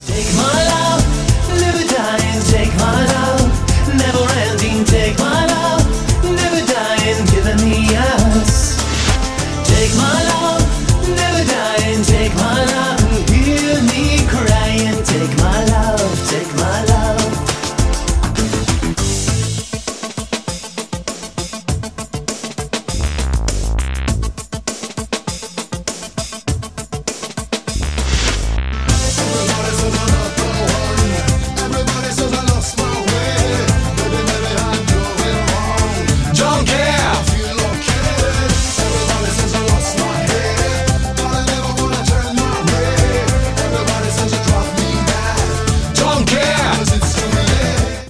• Качество: 192, Stereo
поп
мужской вокал
Synth Pop
веселые
заводные
dance